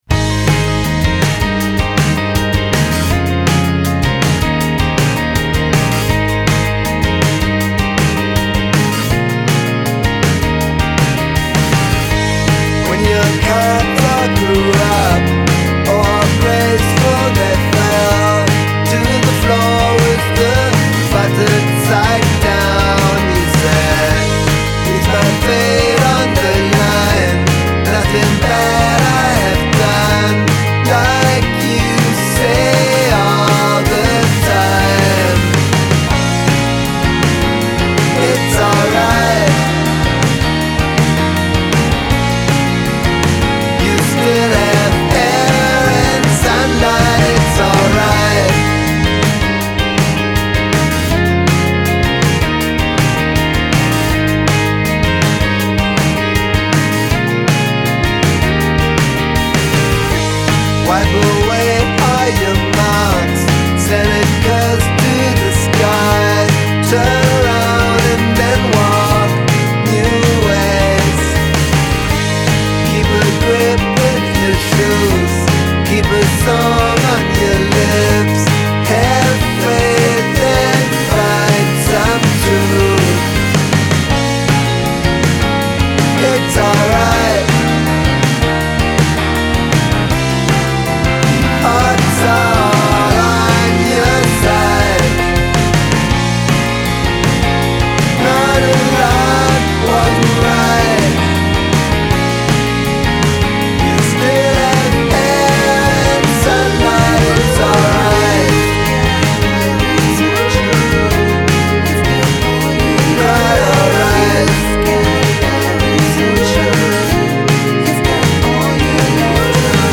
jangly, power-poppish radio and playlist friendly single